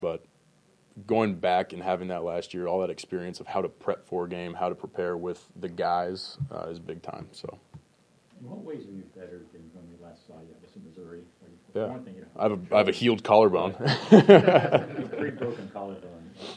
Florida quarterback Graham Mertz previewed a new season of Gator football and the opening matchup against No. 19 Miami in a news conference Monday.